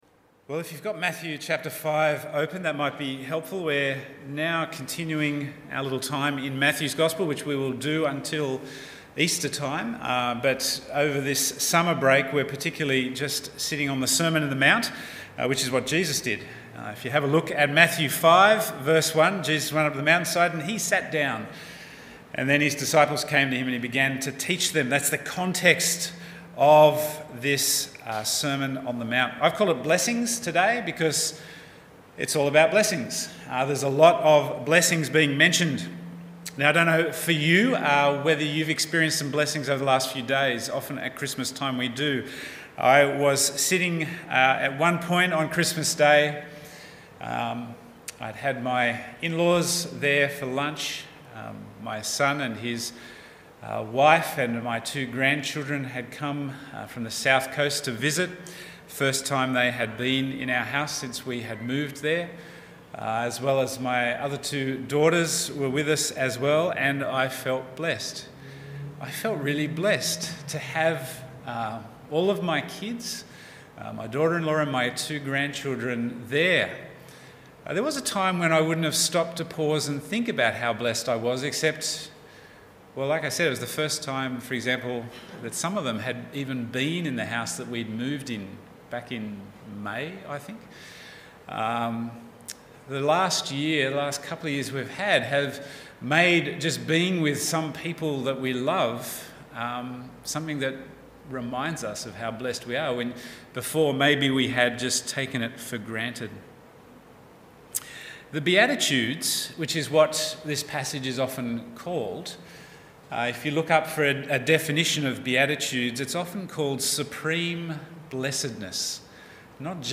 Bible Text: Matthew 5:1-16 | Preacher